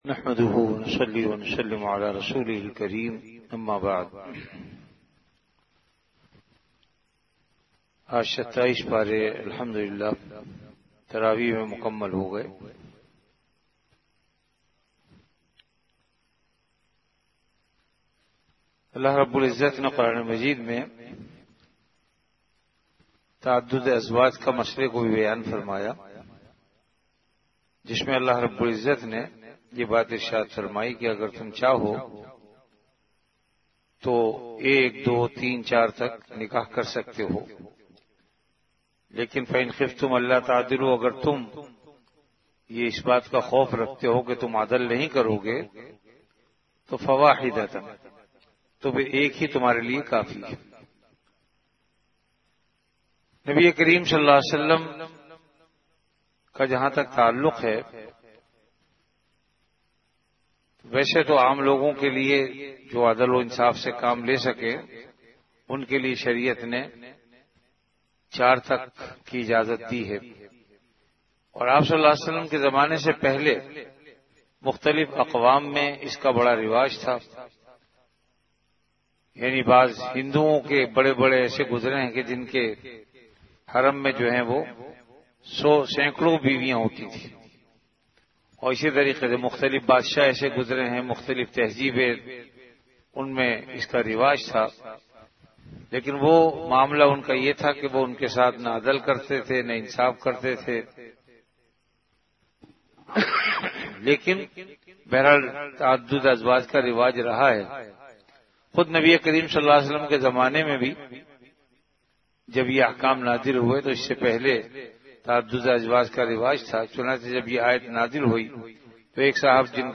An Urdu Islamic audio lecture on Ramadan - Taraweeh Bayan, delivered at Jamia Masjid Bait-ul-Mukkaram, Karachi.
Ramadan - Taraweeh Bayan · Jamia Masjid Bait-ul-Mukkaram, Karachi